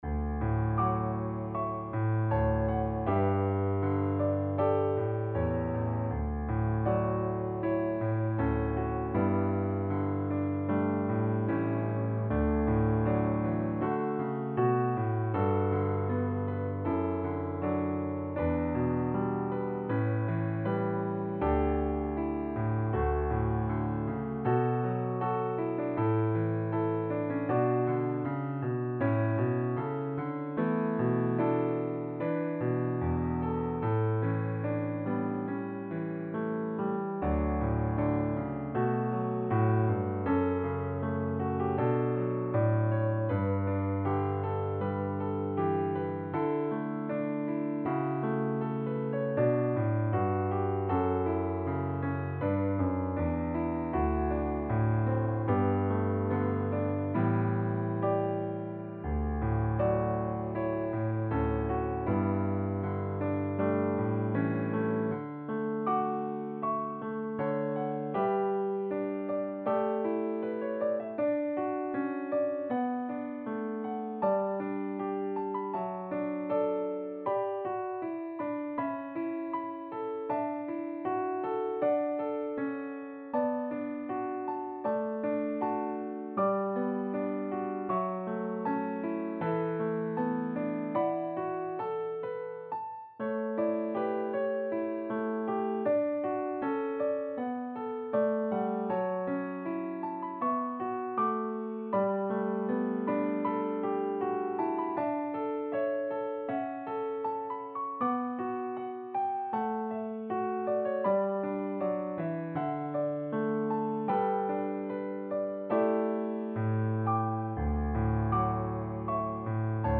gentle and expressive
has a slightly contemporary feel
• Level: Intermediate Piano Solo